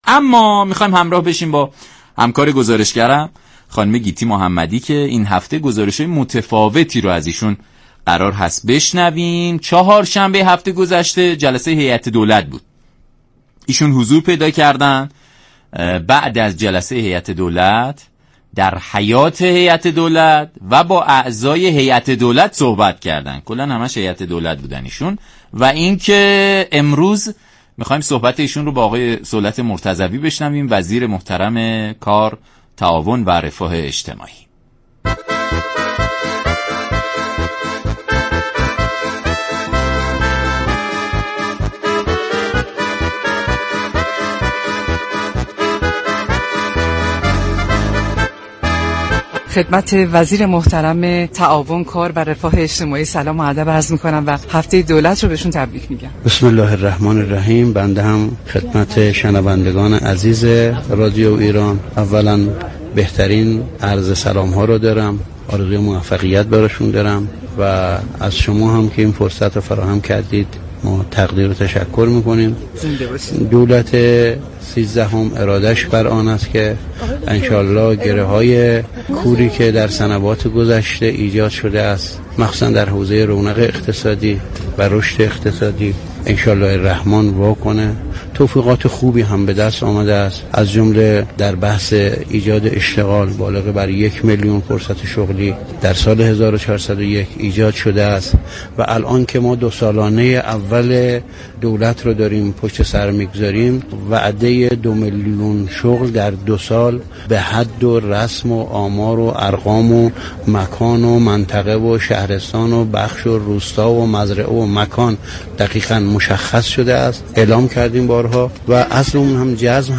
دریافت فایل شخصیت مهم خبری: صولت مرتضوی وزیر كار، تعاون و رفاه اجتماعی گزارشگر